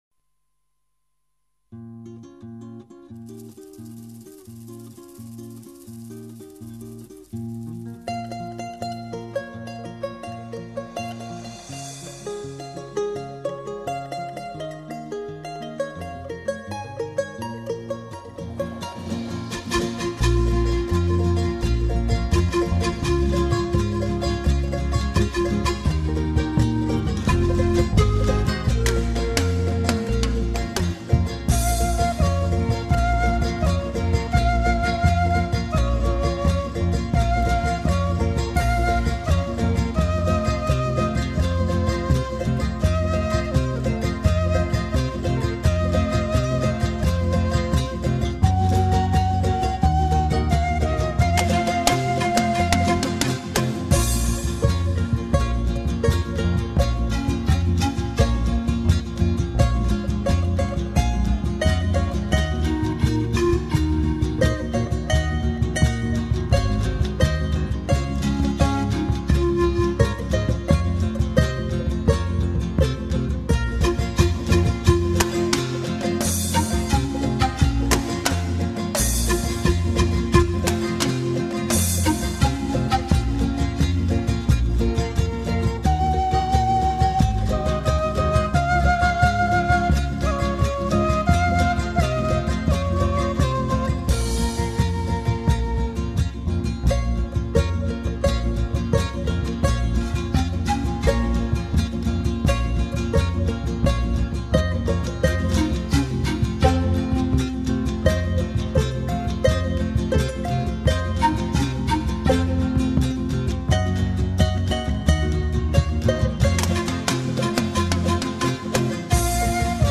Folclore